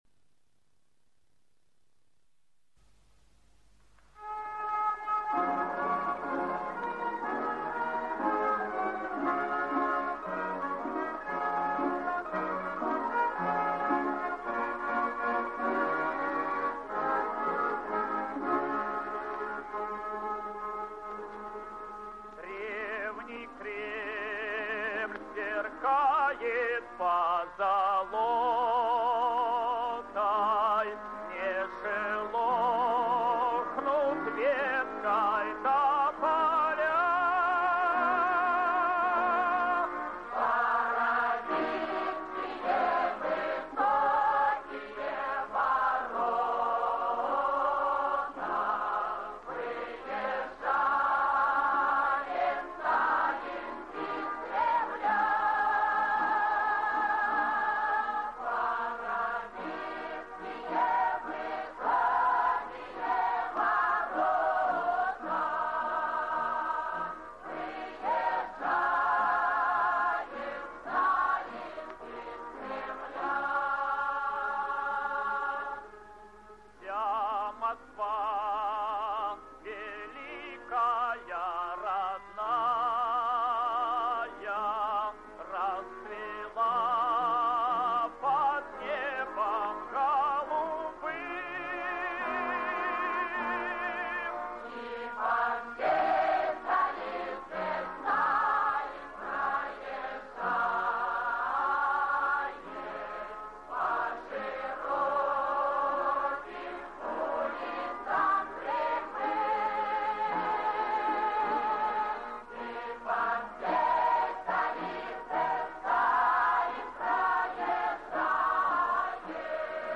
Русский народный хор весьма недурно поет о Сталине